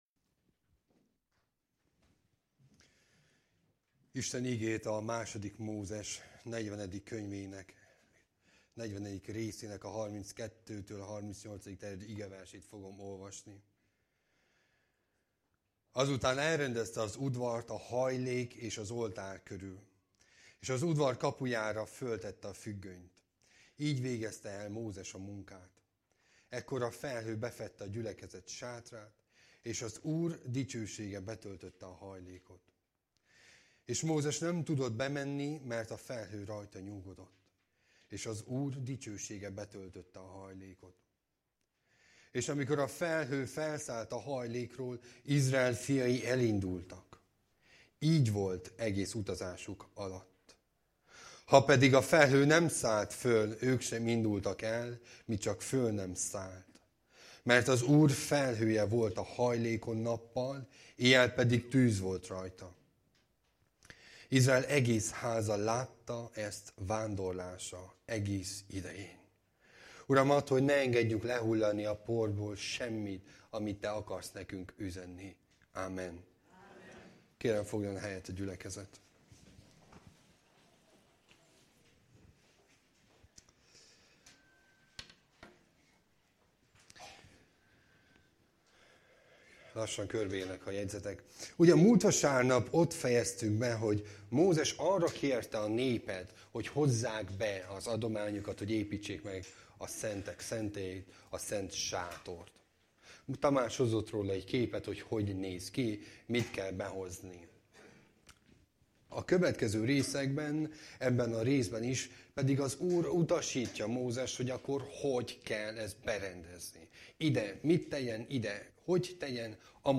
Passage: 2 Mózes 40,32-38 Service Type: Igehirdetés